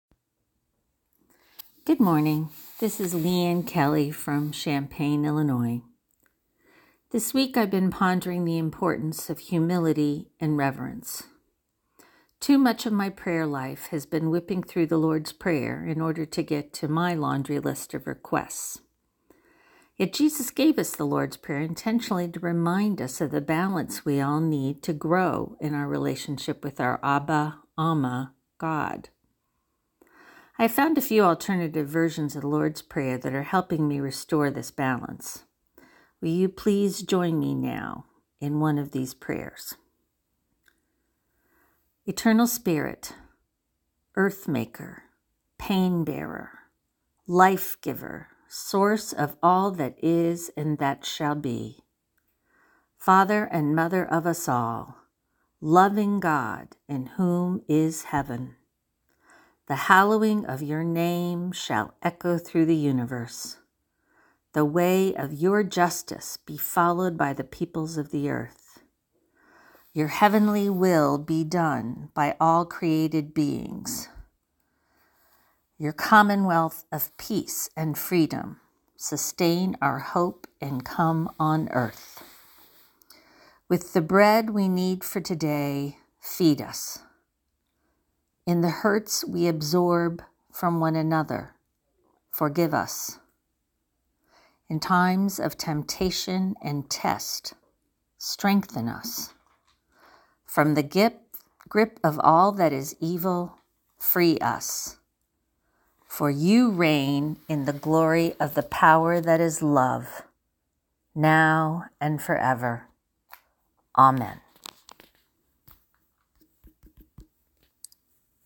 prayer2.11.wav